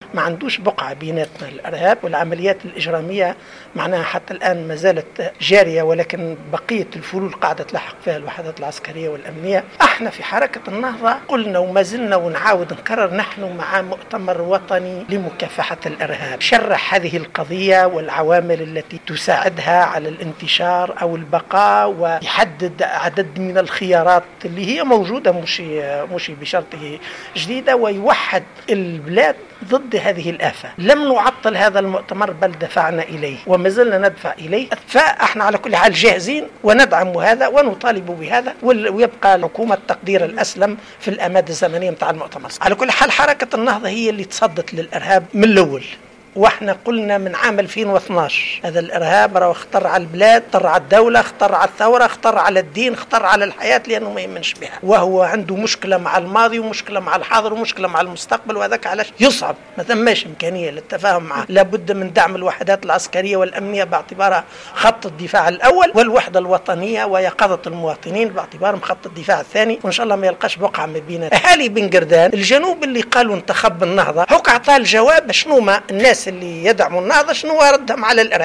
Le secrétaire général du mouvement Ennahdha Ali Larayedh a affirmé, lors d'une déclaration accordée à Jawhara en marge du congrès régional du mouvement tenu ce samedi 12 mars 2016 à Mahdia que son parti a prévenu contre les dangers du terrorisme depuis 2012.